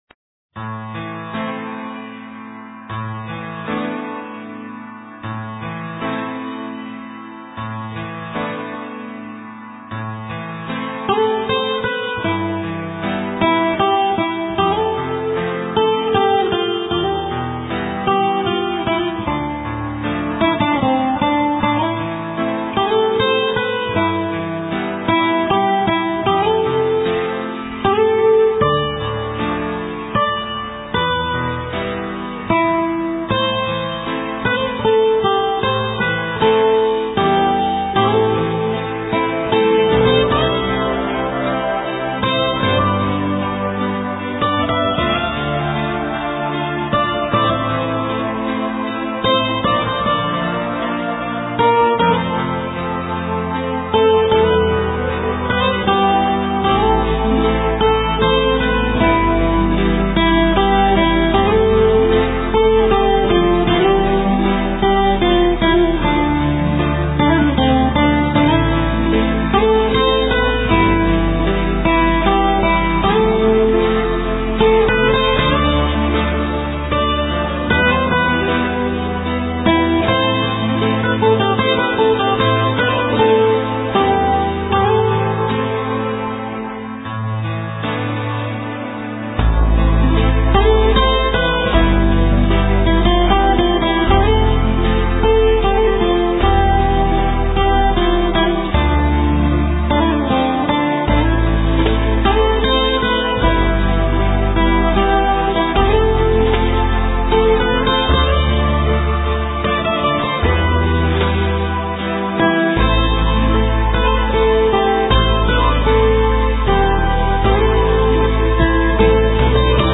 * Thể loại: Ngoại Quốc